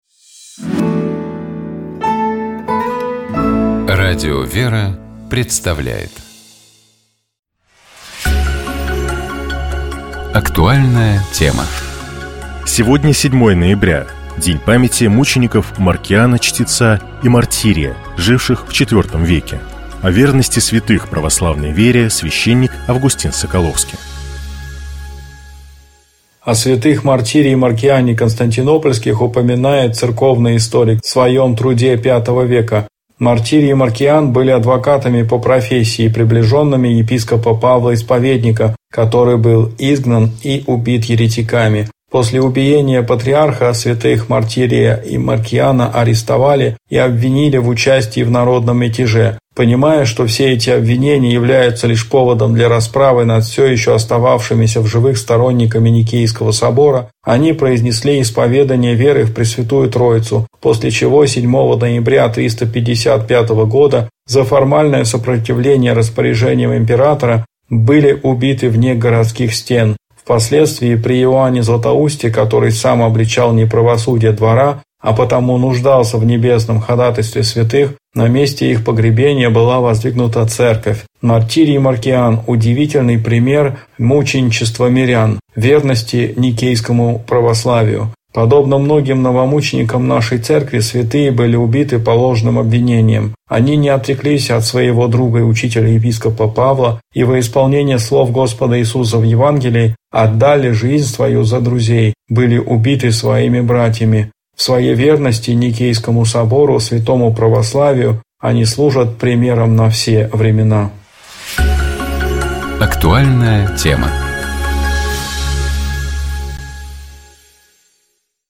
Каждую пятницу ведущие, гости и сотрудники радиостанции обсуждают темы, которые показались особенно интересными, важными или волнующими на прошедшей неделе.